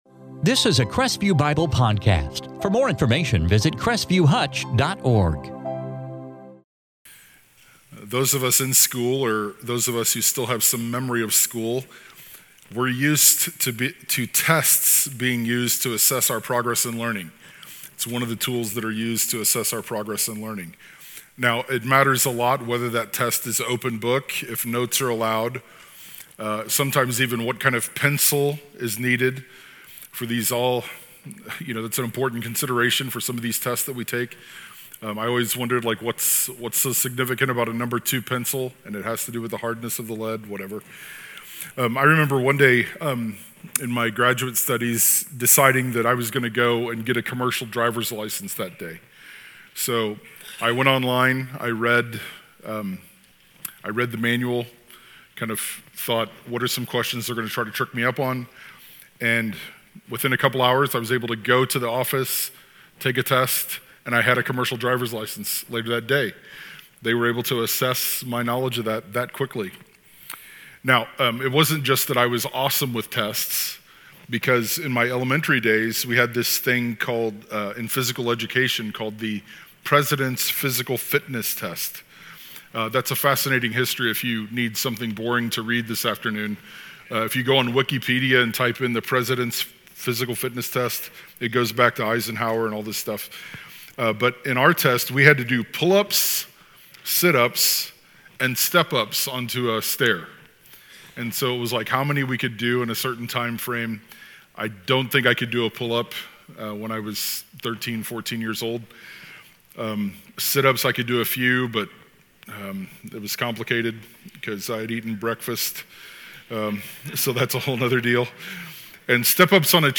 In this sermon from 2 Corinthians 13:5-10